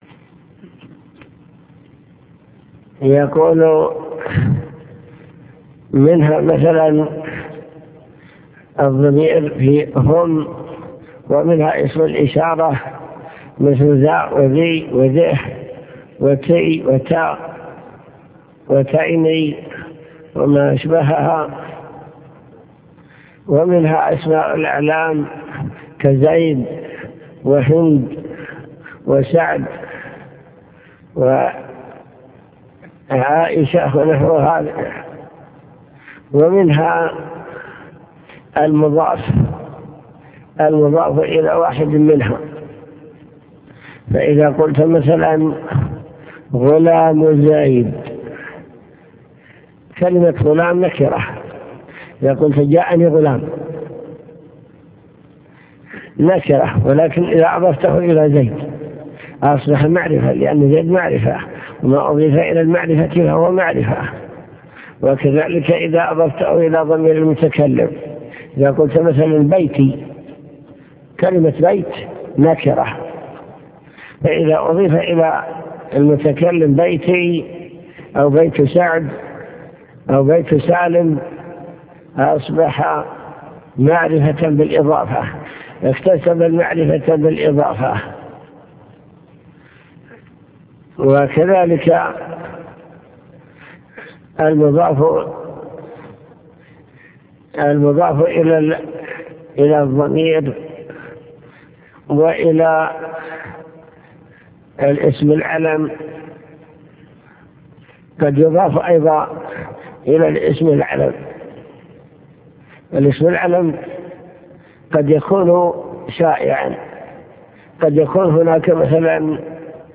المكتبة الصوتية  تسجيلات - كتب  شرح كتاب الآجرومية النكرة والمعرفة أنواع المعارف